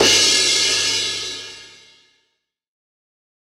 DJP_PERC_ (7).wav